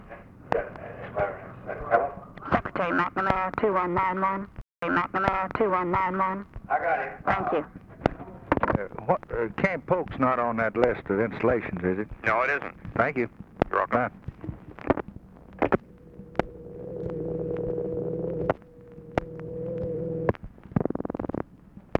Conversation with ROBERT MCNAMARA, December 10, 1963
Secret White House Tapes